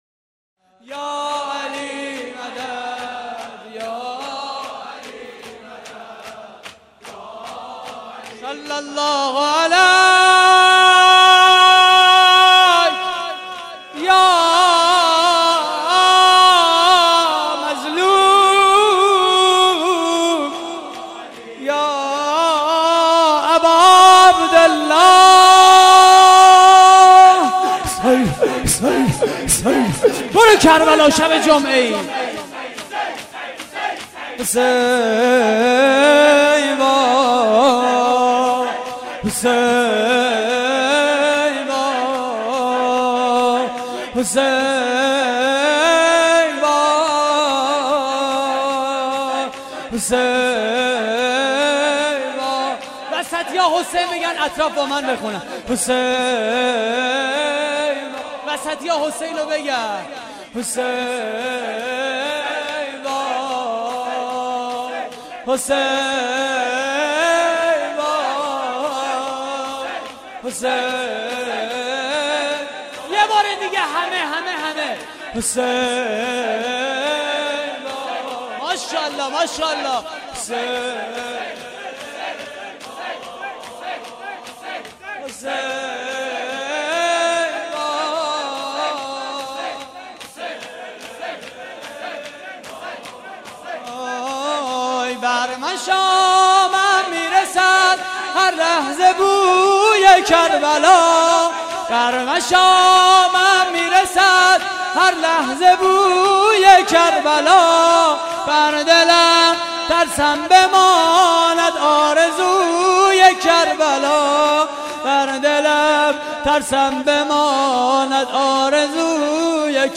مداحی شب 21 رمضان (شهادت حضرت امیر ع) / هیئت کریم آل طاها (ع) - 19 مرداد 91
صوت مراسم:
شور: بر مشامم می‌رسد هر لحظه بوی کربلا؛ پخش آنلاین |